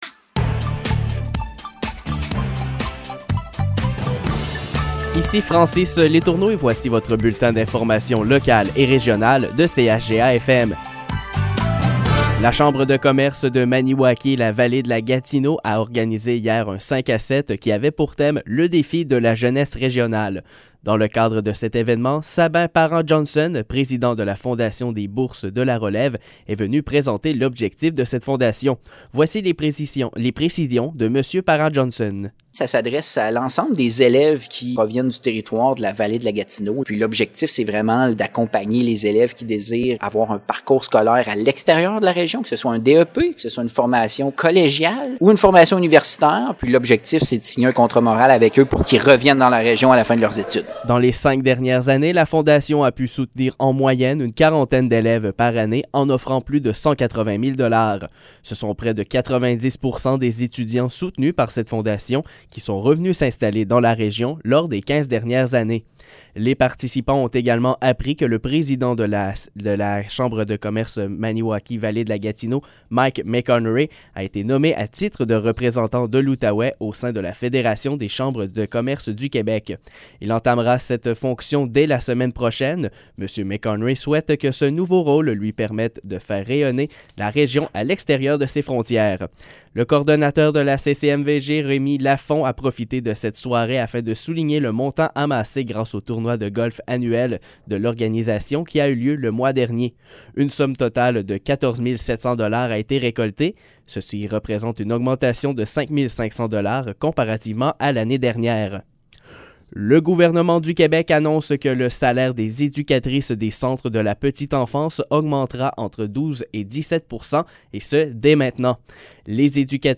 Nouvelles locales - 14 octobre 2021 - 15 h